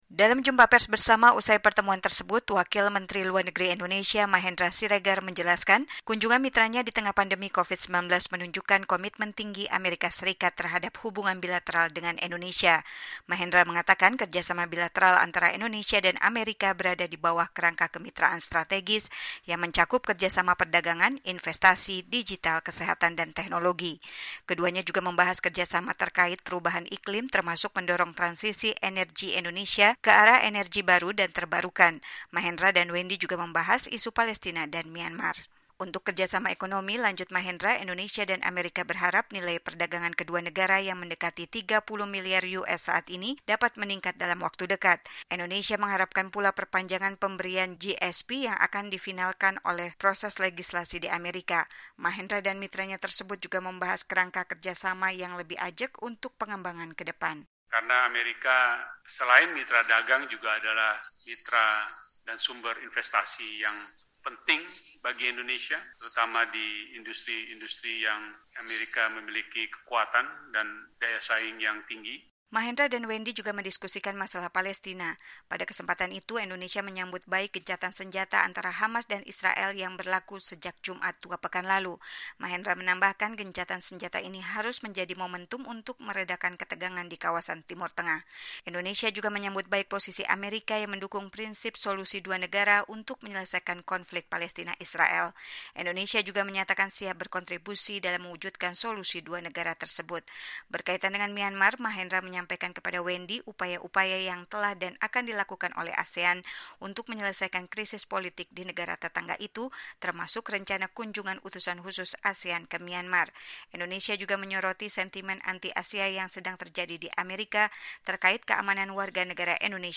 Dalam jumpa pers bersama usai pertemuan tersebut, Wakil Menteri Luar Negeri Indonesia Mahendra Siregar menjelaskan kunjungan mitranya di tengah pandemi COVID-19 menunjukkan komitmen tinggi Amerika Serikat (AS) terhadap hubungan bilateral dengan Indonesia.